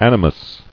[an·i·mus]